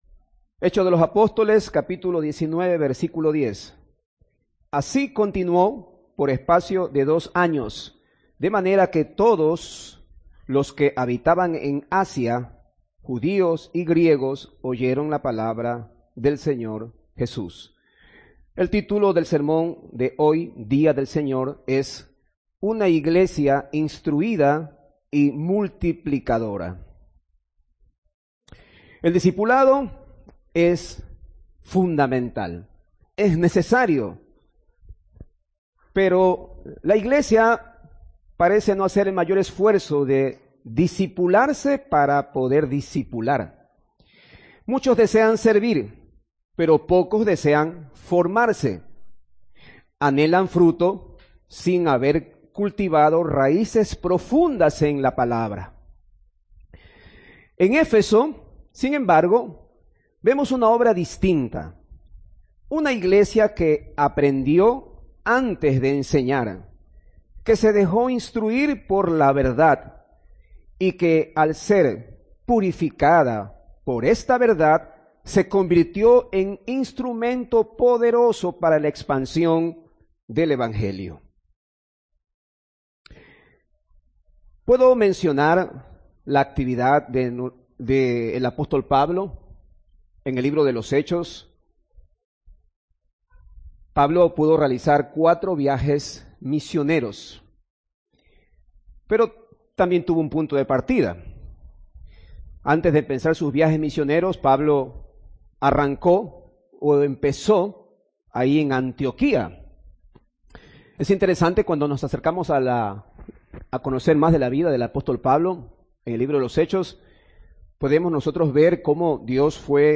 Audio del sermón